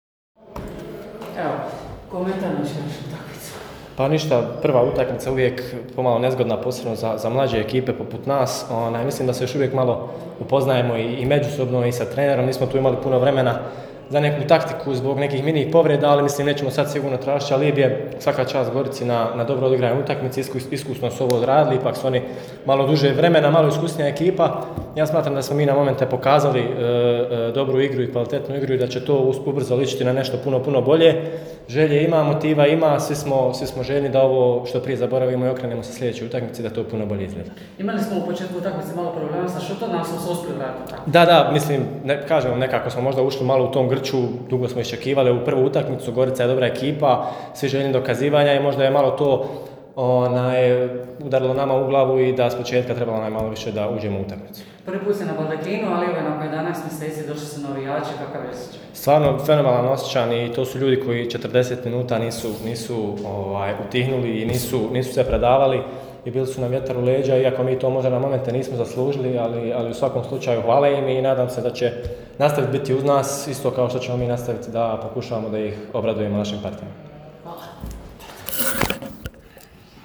IZJAVE: